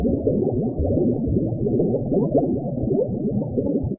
Underwater Bubbles
Air bubbles rising through water with gurgles, pops, and muffled aquatic ambience
underwater-bubbles.mp3